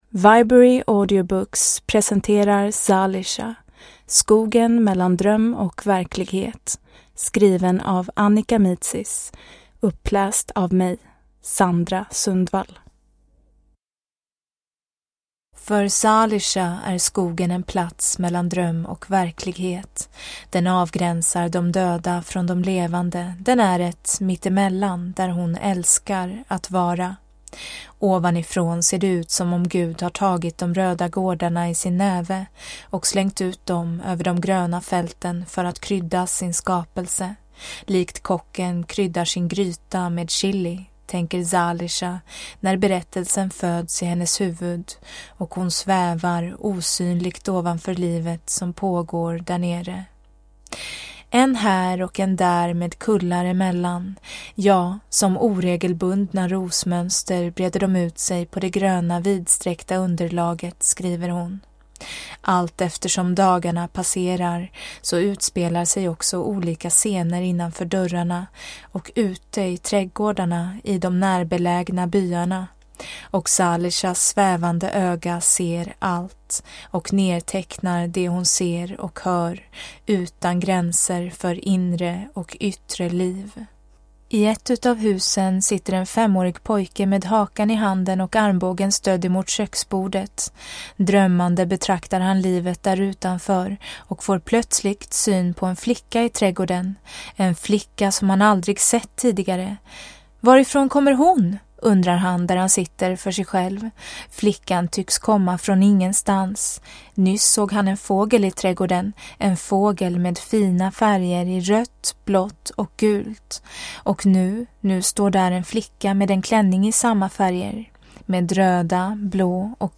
Zalisha – skogen mellan dröm och verklighet – Ljudbok